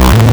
Player_Glitch [83].wav